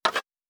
Weapon Draw Wood 02.wav